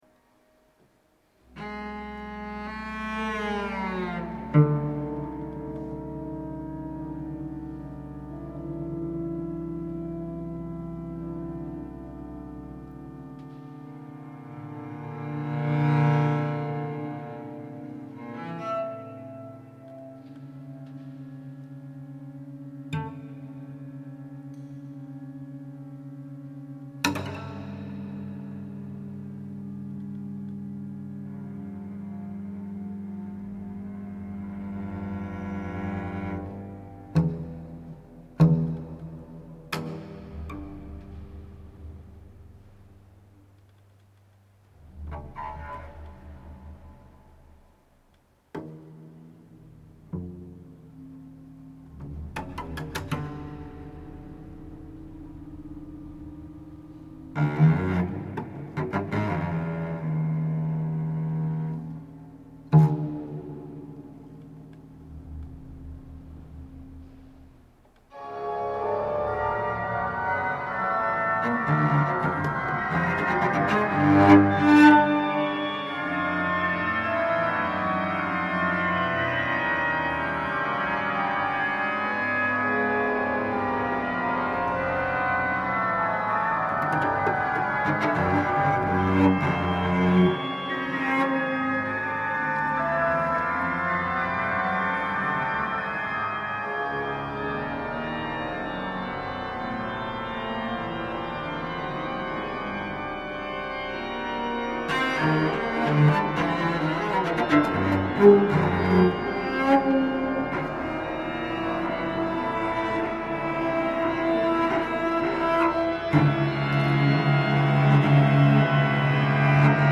für Violoncello und Orgel, 21'